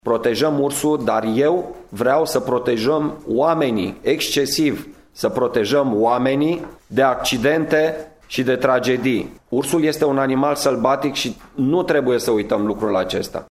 Prefectul judeţului, Marian Rasaliu: